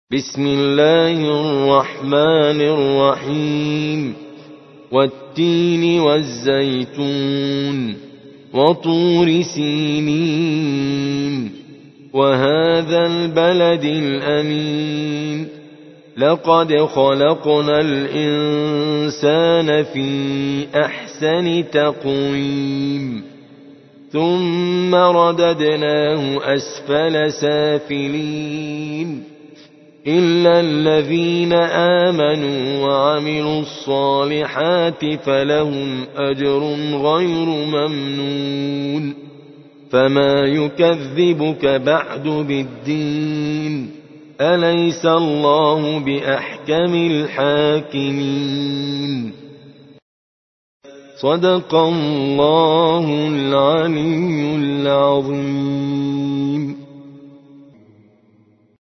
95. سورة التين / القارئ